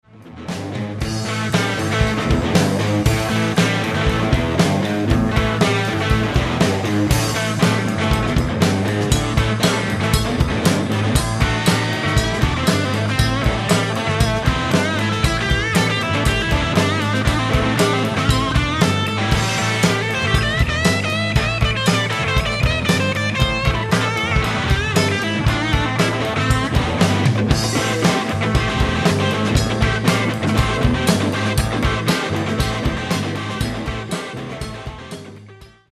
Lead guitarist, vocalist, and songwriter.
We bridge blues and rock."
Rhythm guitarist. .
Drummer.
Bassist.